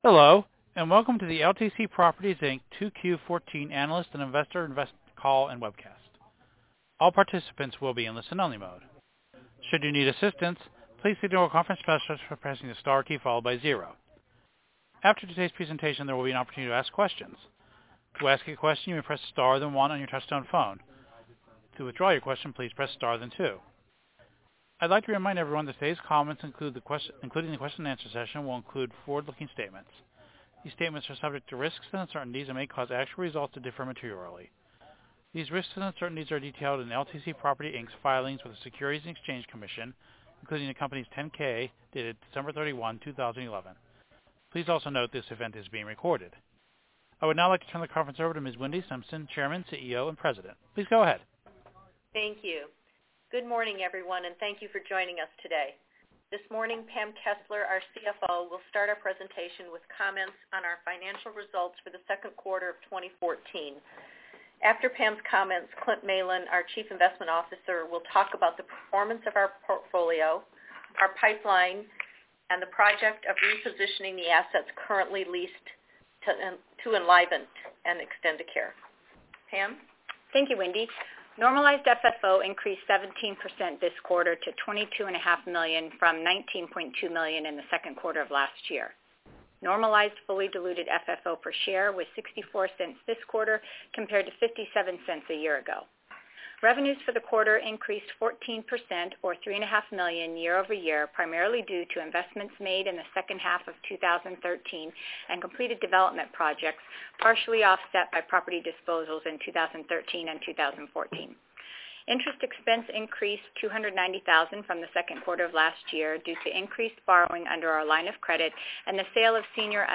Earnings Webcast Q2 2014 Audio
LTC-Q2-2014-Earnings-Call.mp3